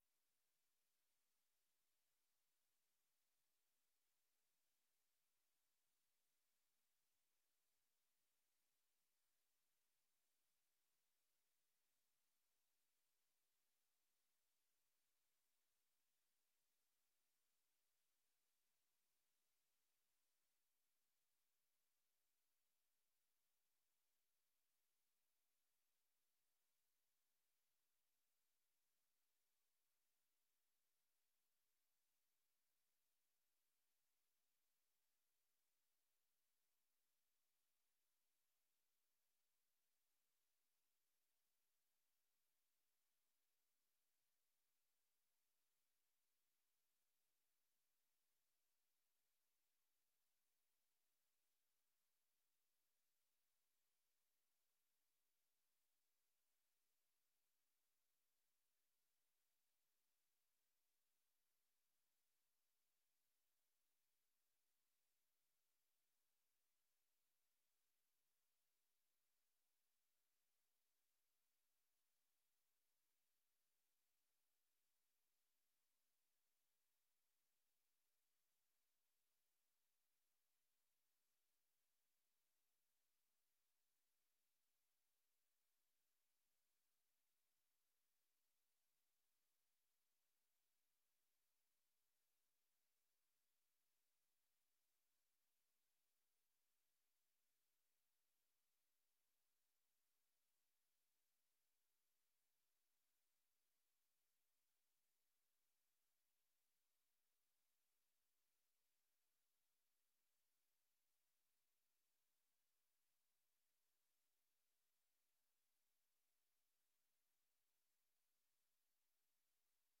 Locatie: Raadzaal Voorzitter: Henk de Man